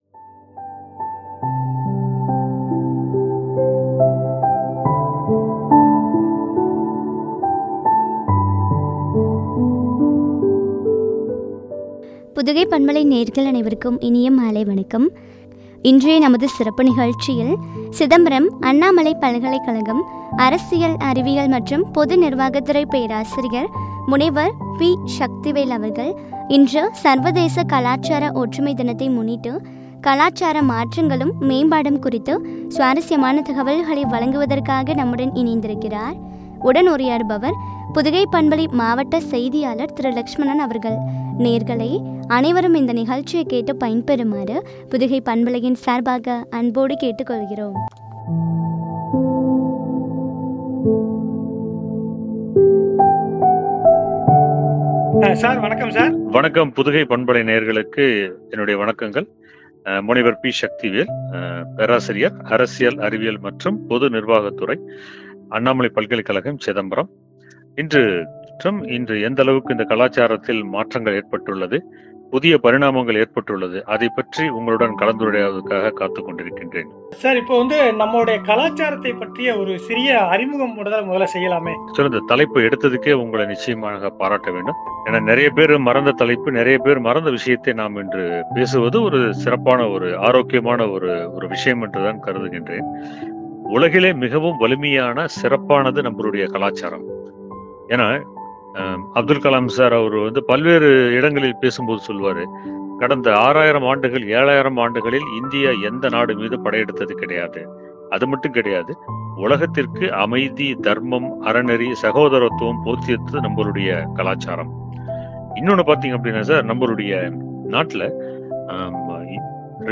மேம்பாடும் குறித்து வழங்கிய உரையாடல்.